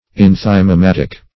Meaning of enthymematic. enthymematic synonyms, pronunciation, spelling and more from Free Dictionary.